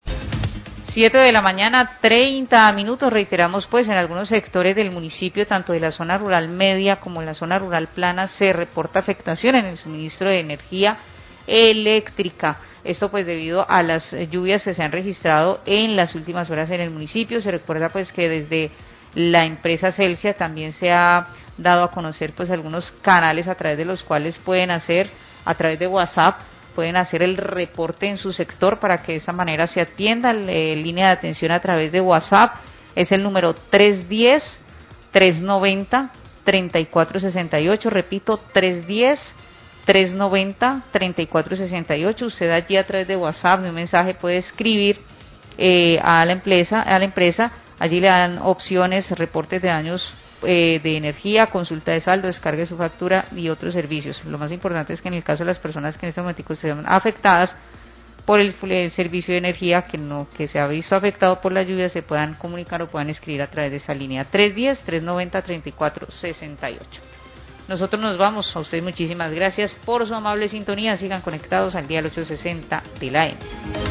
Periodista informa que cortes de energía por lluvias de hoy lo pueden reporta por whatsapp
Radio